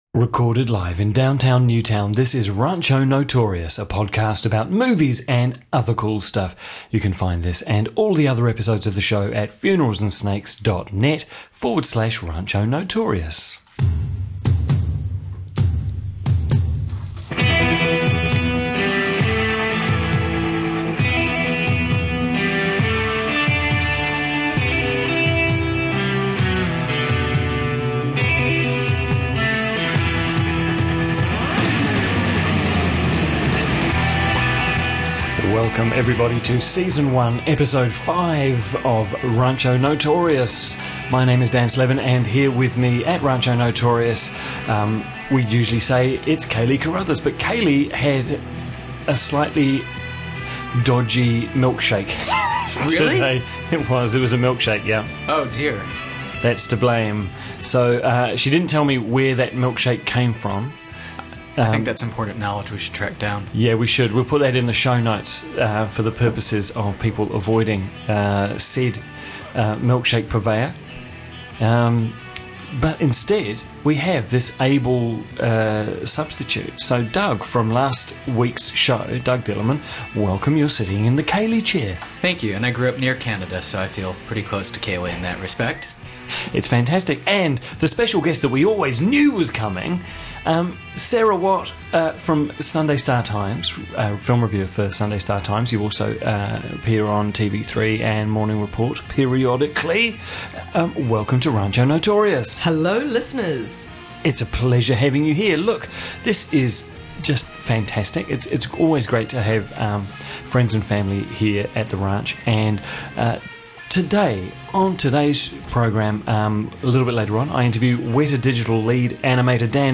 We need a clock in the studio.